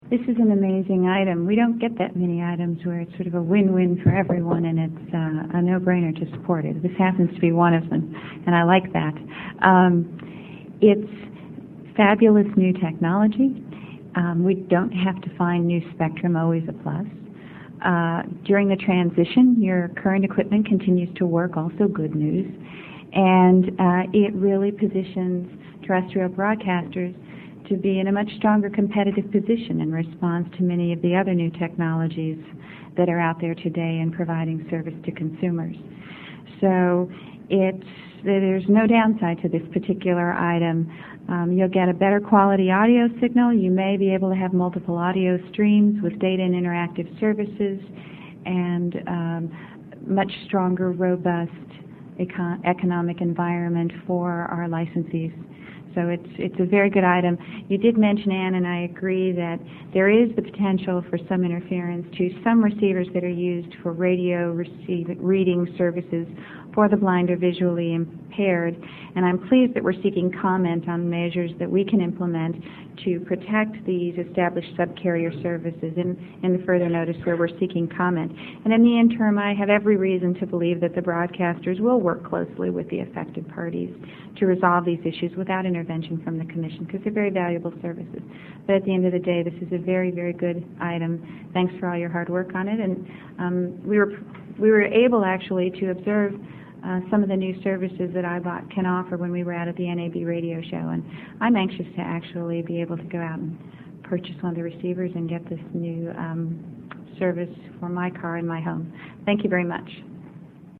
Note: All audio is in 48kbps/44KHz mono MP3 format.
FCC Commissioner Kathleen Abernathy (1:50, 650K)